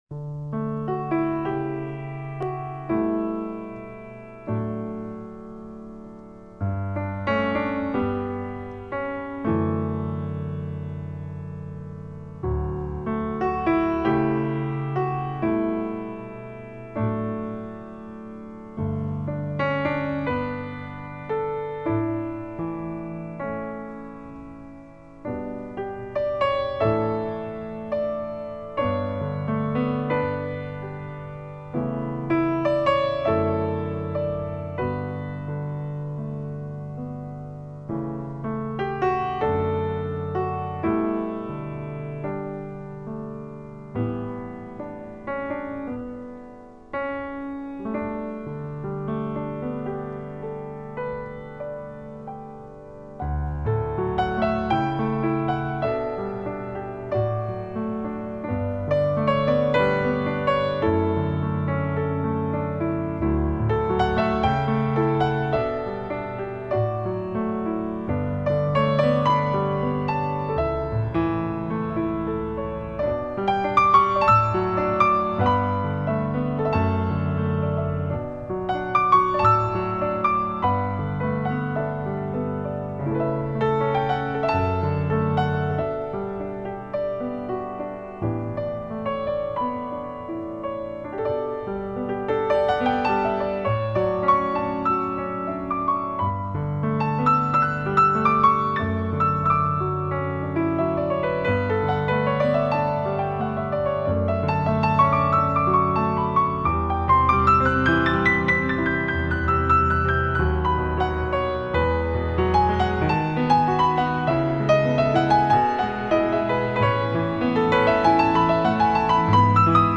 并且回到亲切的钢琴独奏从而表现出一种释然的情怀。